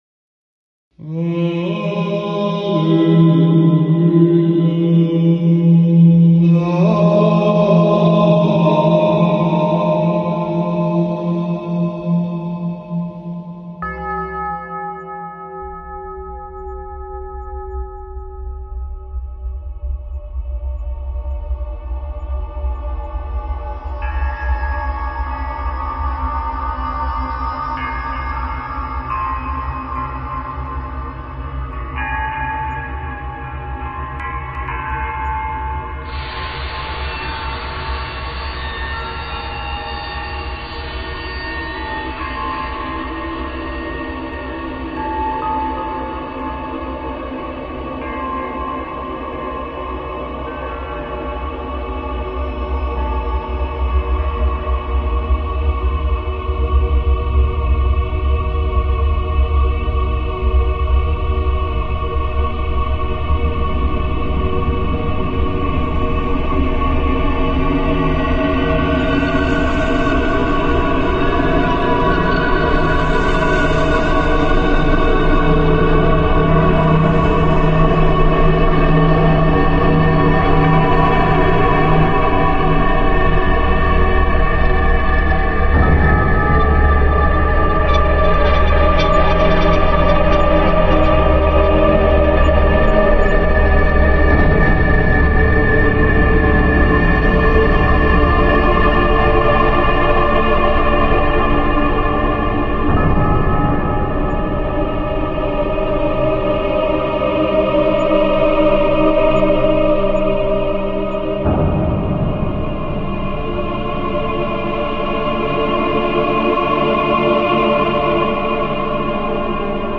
leva o ouvinte a um misterioso e sombrio encanto.